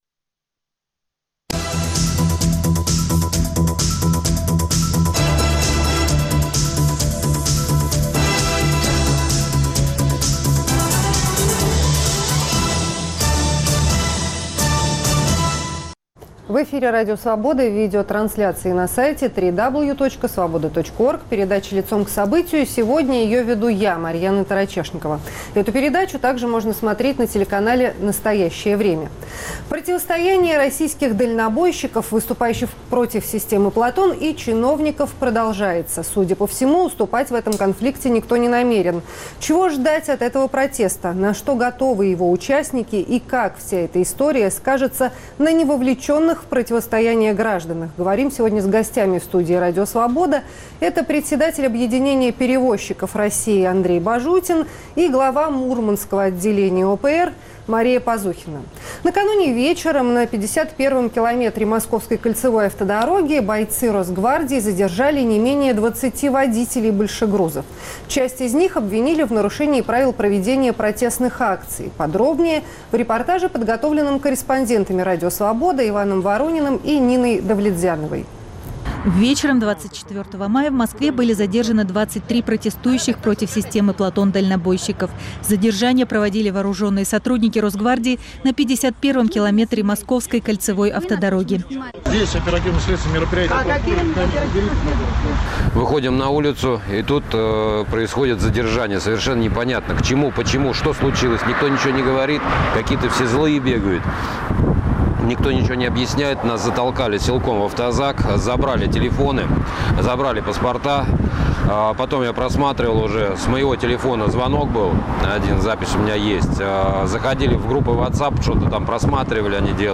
В студии Радио Свобода